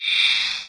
MAGIC SPELL Bright Sci-Fi Subtle (stereo).wav